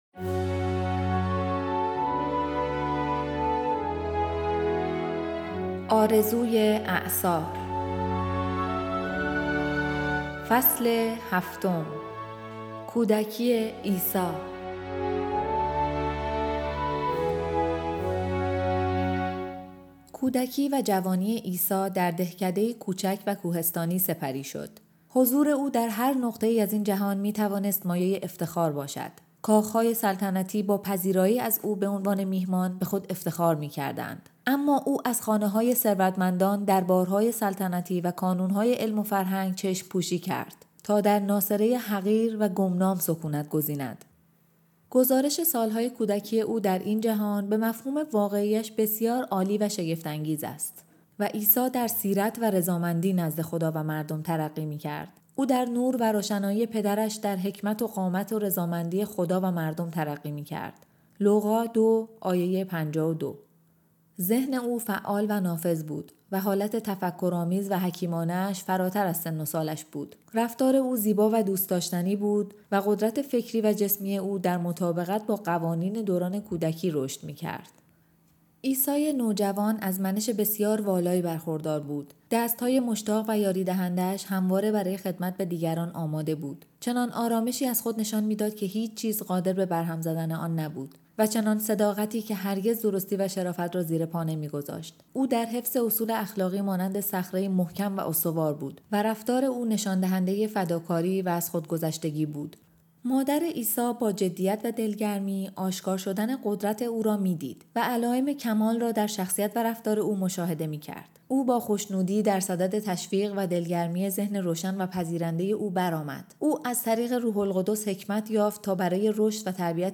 کتاب صوتی : "آرزوی اعصار"